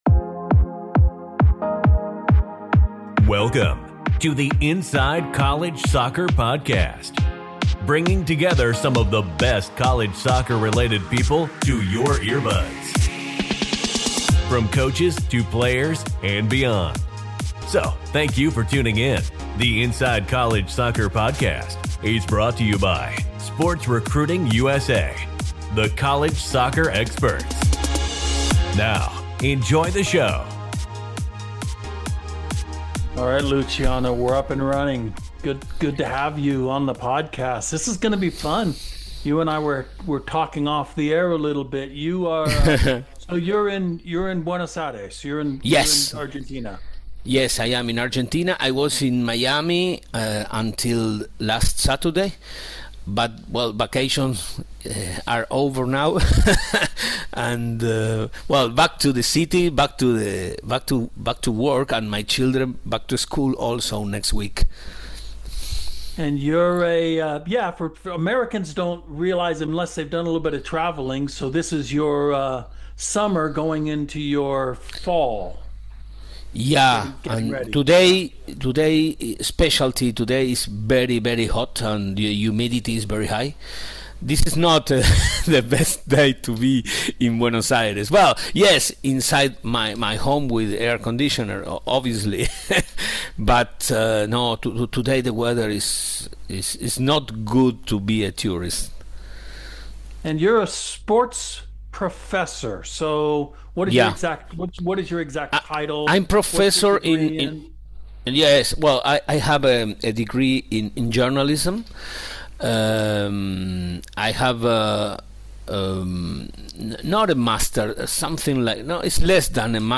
The conversation explores Messi’s defining decision at 14 to leave Argentina for FC Barcelona, and how elite environments, culture, and long-term belief systems create world-class players.